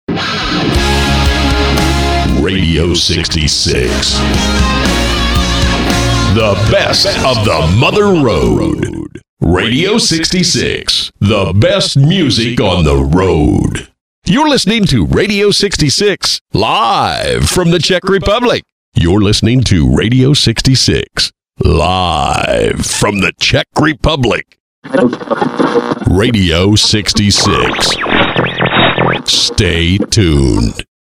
ukázky nových jinglů.